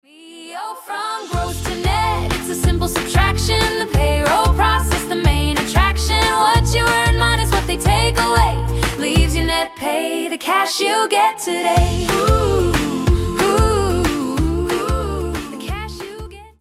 Features two incredibly catchy tracks